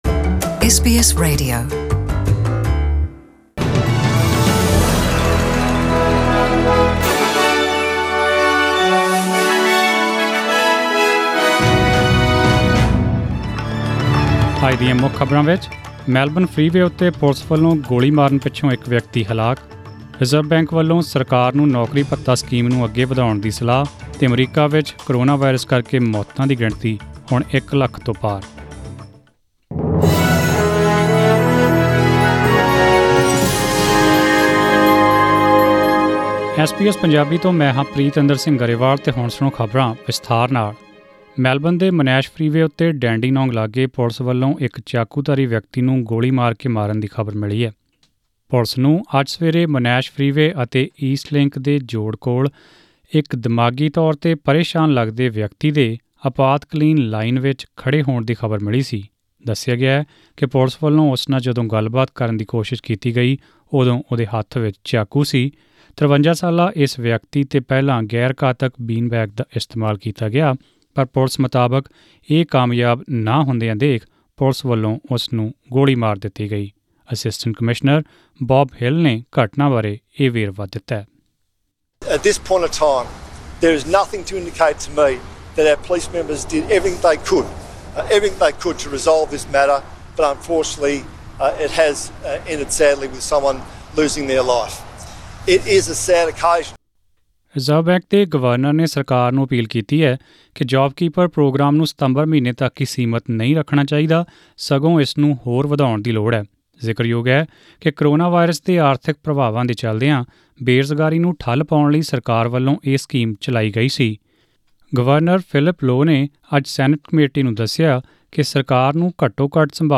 Australian News in Punjabi: 28 May 2020